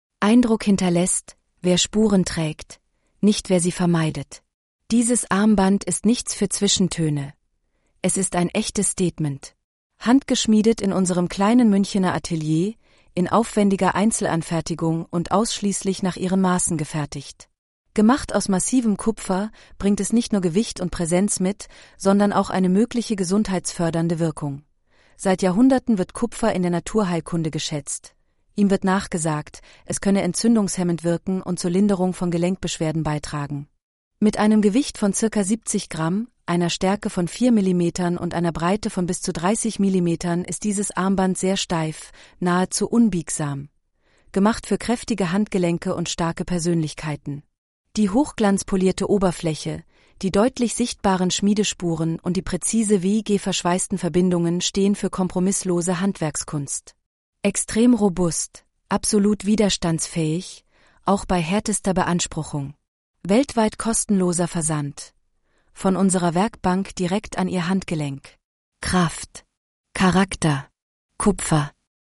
Boreas-ttsreader.mp3